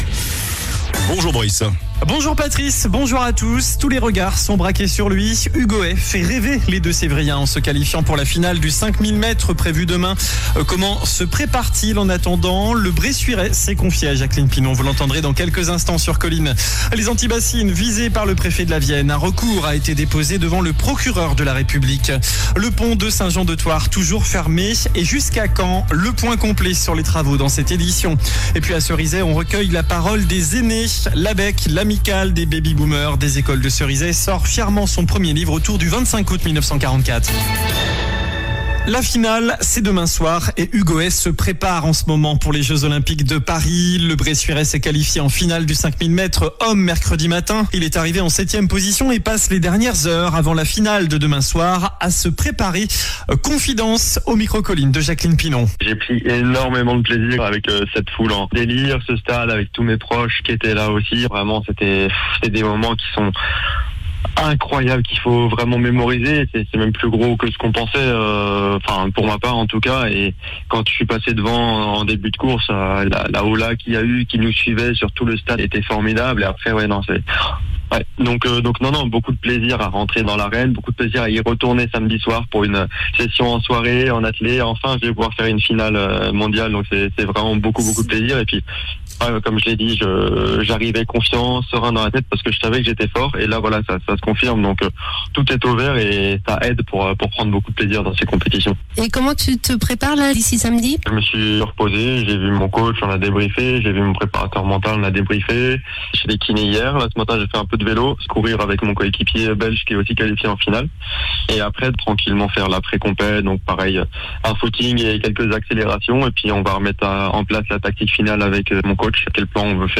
JOURNAL DU VENDREDI 09 AOÛT ( MIDI )